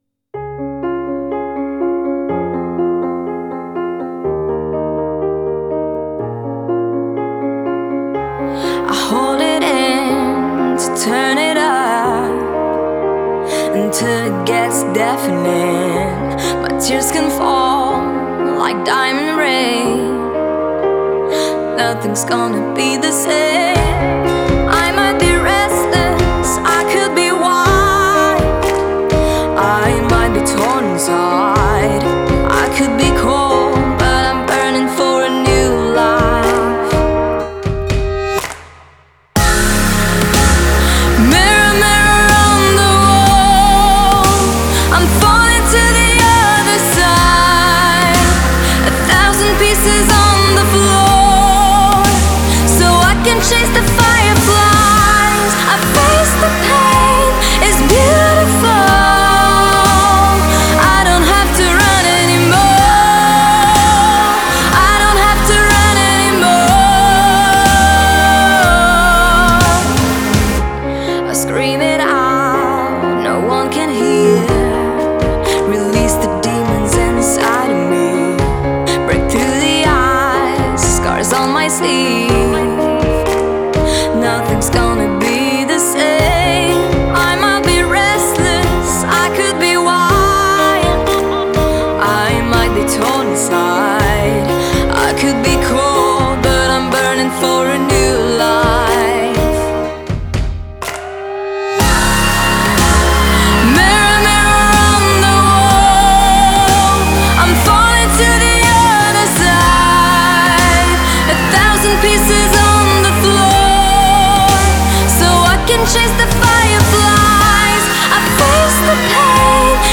Genre: Pop, Female vocalists